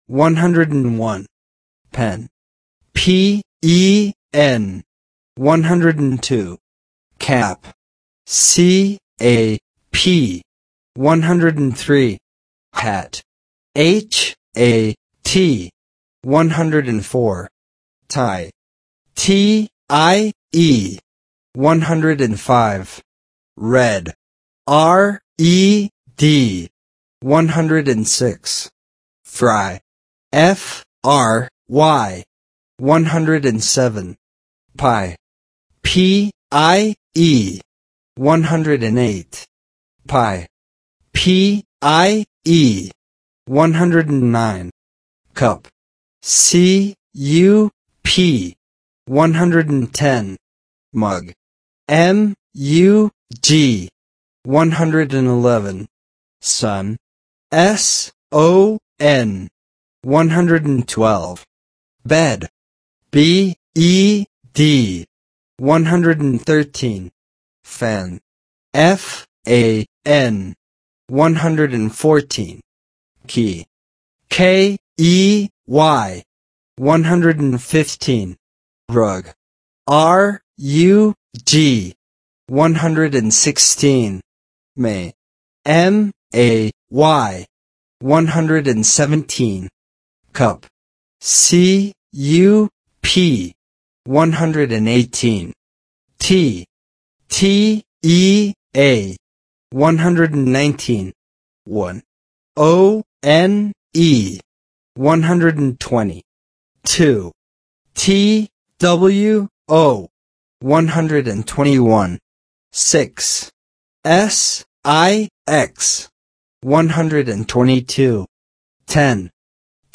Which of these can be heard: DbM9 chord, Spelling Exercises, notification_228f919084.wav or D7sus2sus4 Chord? Spelling Exercises